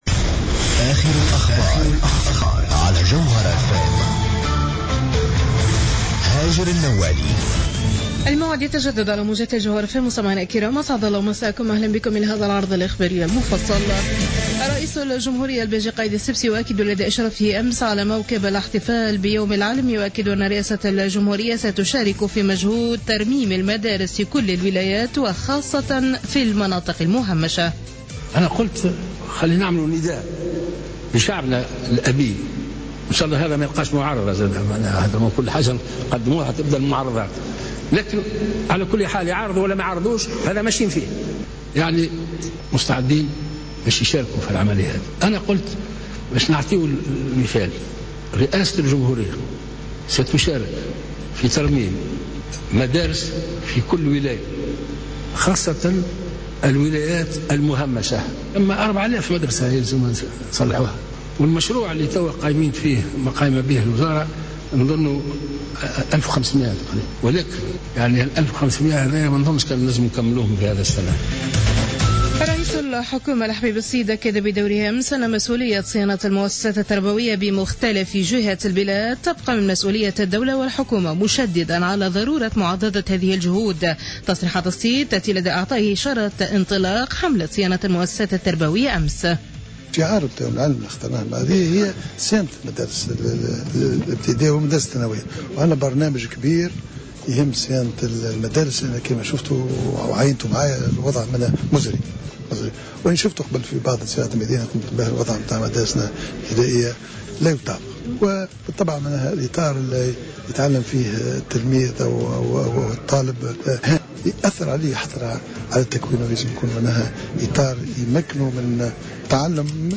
نشرة أخبار منتصف الليل ليوم الخميس 23 جويلية 2015